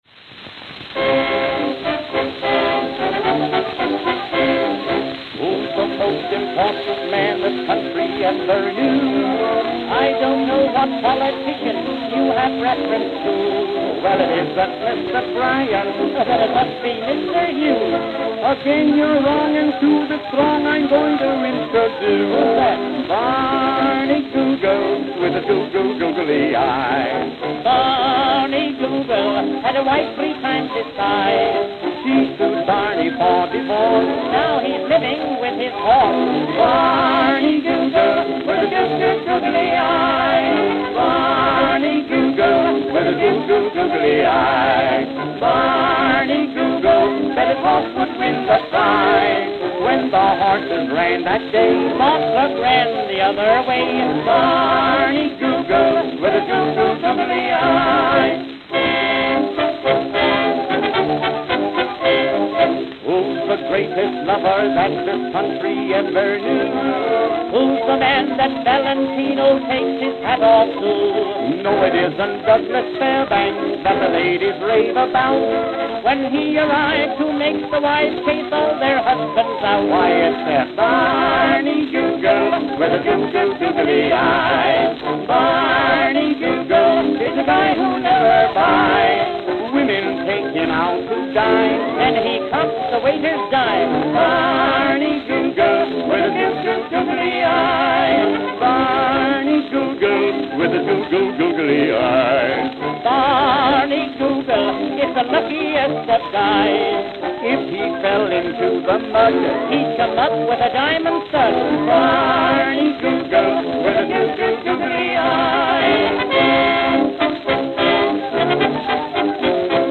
I even only use the left channel when transferring these.
Note: Worn.